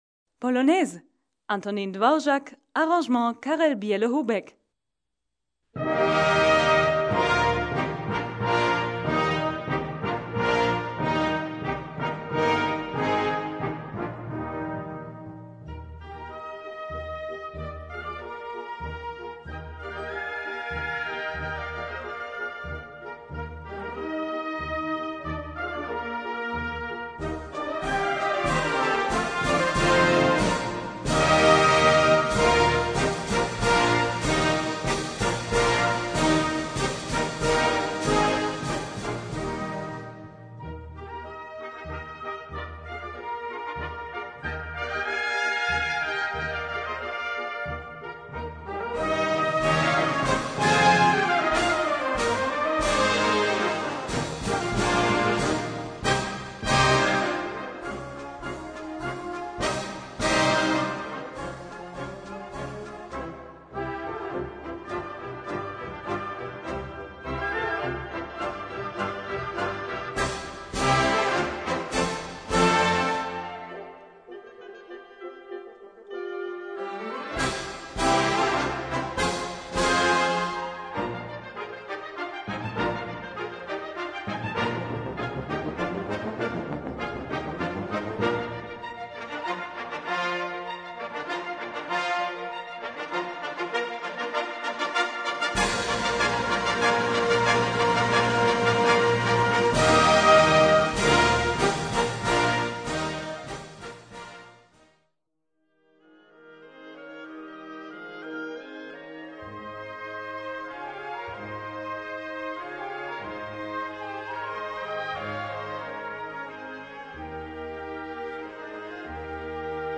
Gattung: Polonaise
Besetzung: Blasorchester
großes Blasorchester